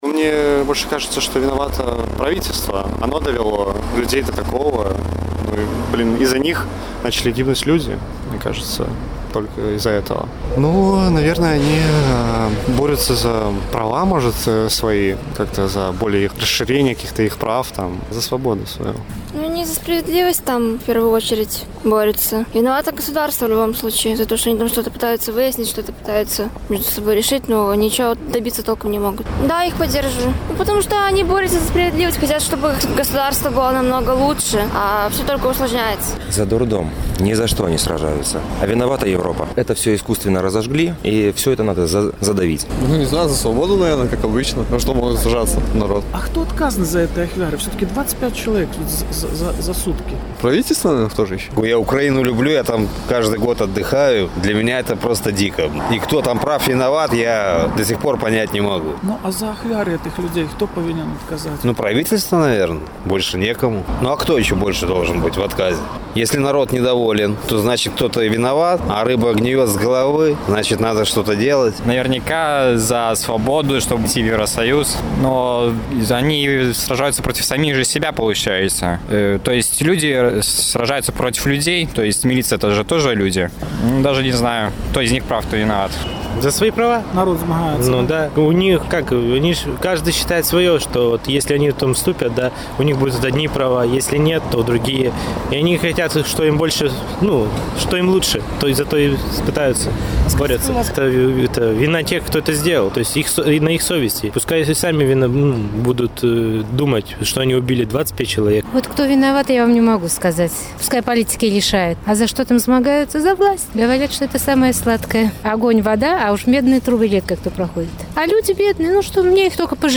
Хто вінаваты ў ахвярах падчас сутычак у Кіеве, за што ідзе змаганьне? З такімі пытаньнямі наш карэспандэнт зьвяртаўся да гарадзенцаў.